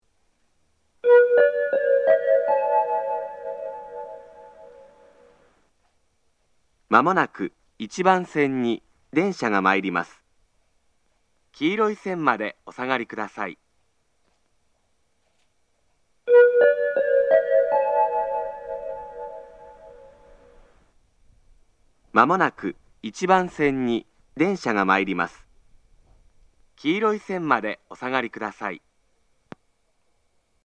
自動放送
仙石旧型（男性）
接近放送
仙石旧型男性の接近放送です。同じ内容を2度繰り返します。